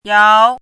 “轺”读音
yáo
轺字注音：ㄧㄠˊ
国际音标：jɑu˧˥